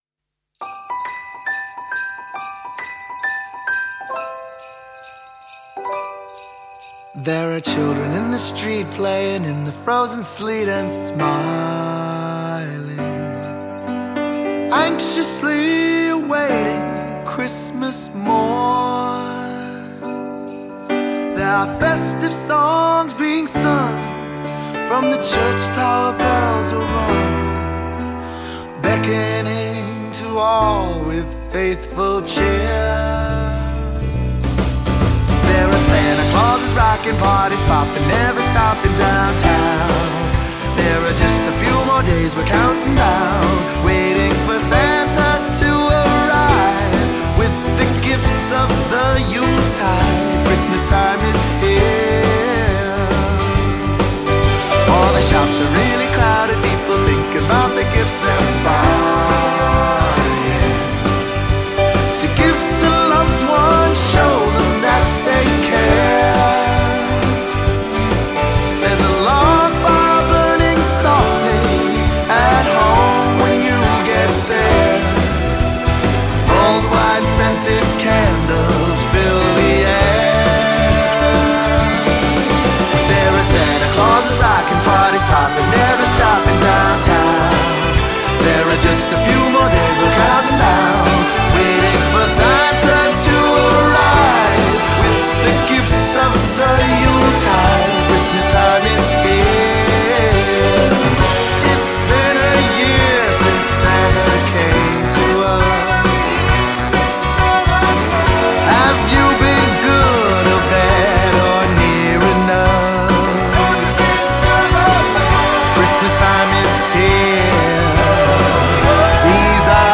IRISH-BASED NYC VOCALIST
This festive anthem